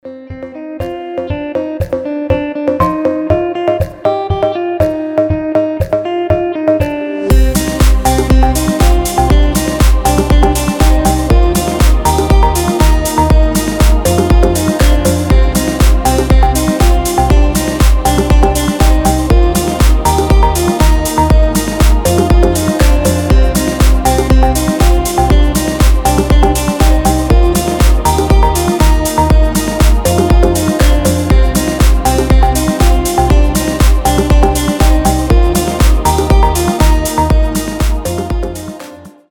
• Качество: 320, Stereo
deep house
без слов
красивая мелодия
Стиль: deep house